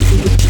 Key-bass_73.4.1.wav